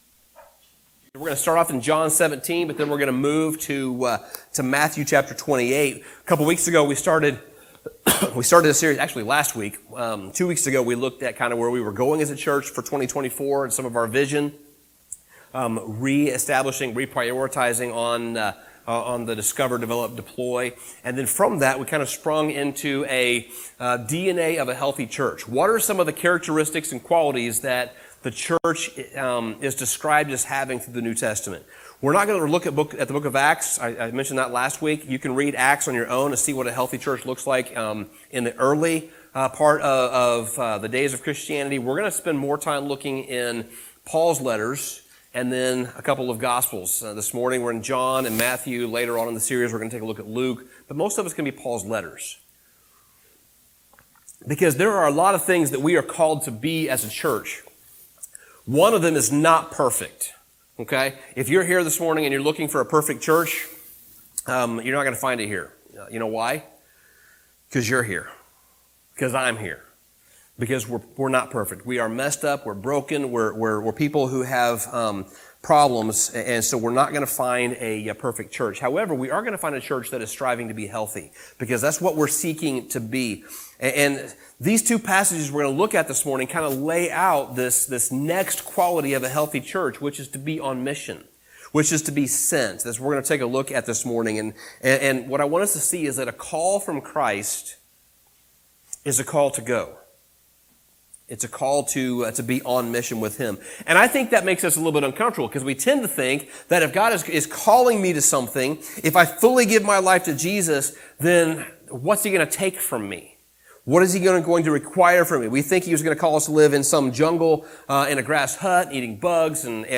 Sermon Summary The message of the Gospel, that we looked at in Week 1, is critically important. And the manner in which God has chosen to spread the message of the Good News is through his church, his disciples.